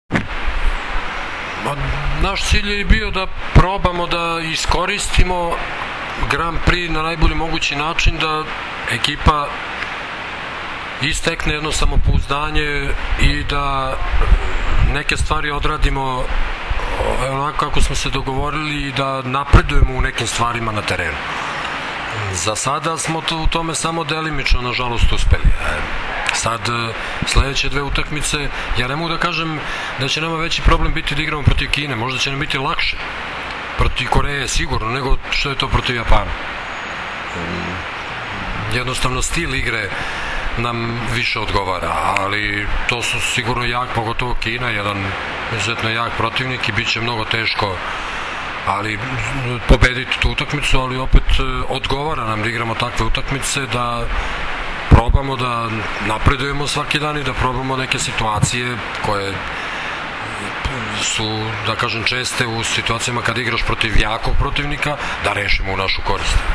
IZJAVA ZORANA TERZIĆA POSLE MEČA